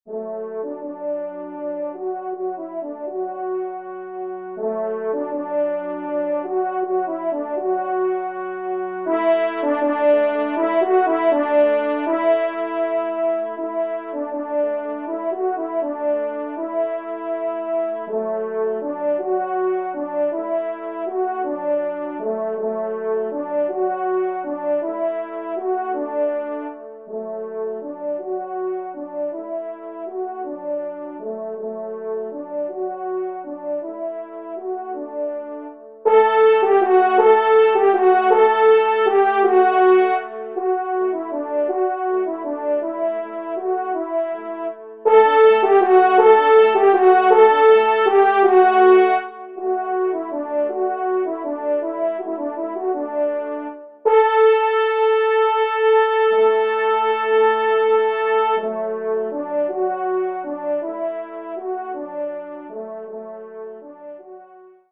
Genre :  Divertissement pour Trompes ou Cors en Ré
1e Trompe